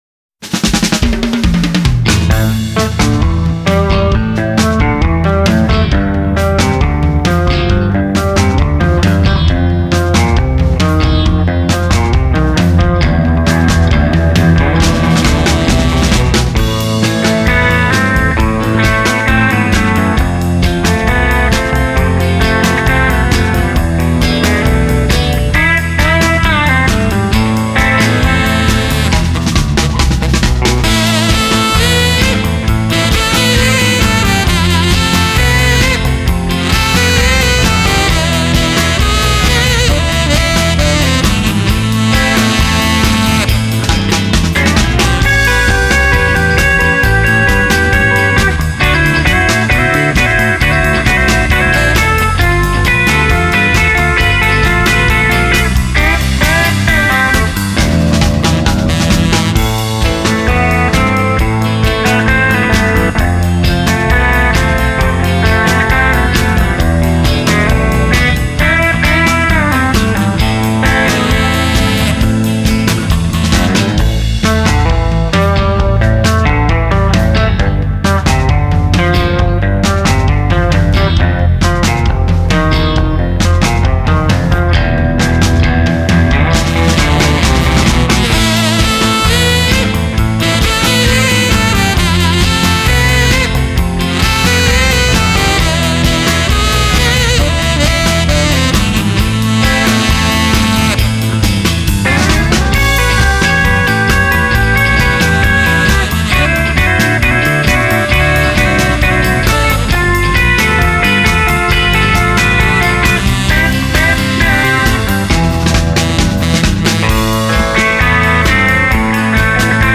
an American instrumental rock band
Genre: Instrumental